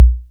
KR55_BD_03.wav